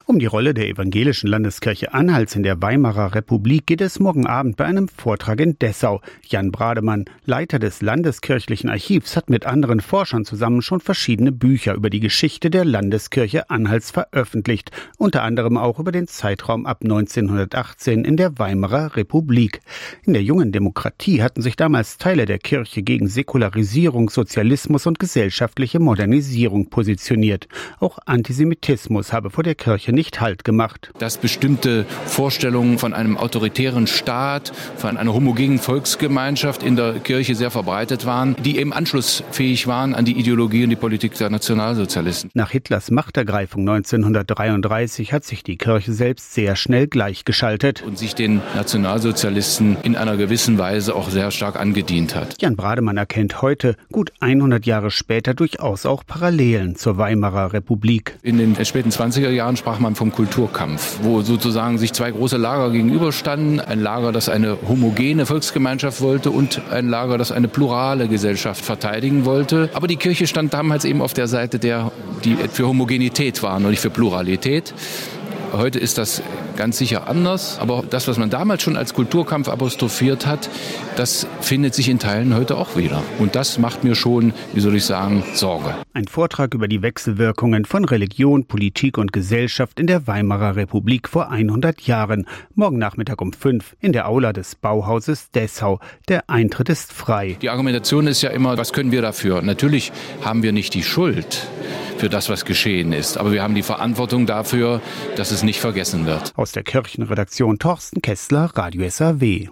Interviewte